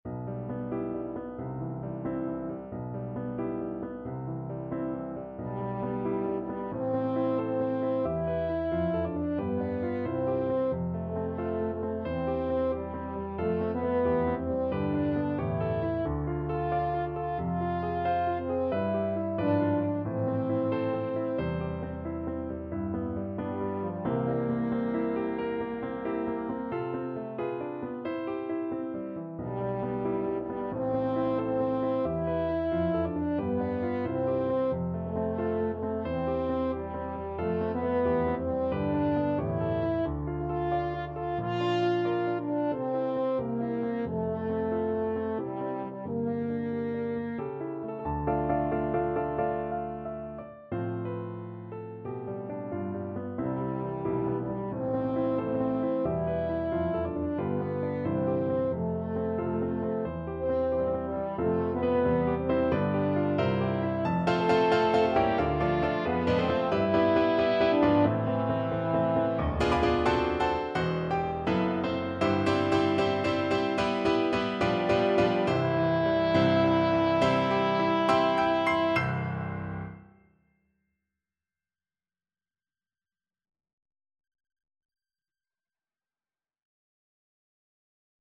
~ = 100 Moderato =90
4/4 (View more 4/4 Music)
Classical (View more Classical French Horn Music)